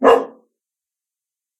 bark1.ogg